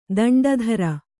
♪ daṇḍa dhara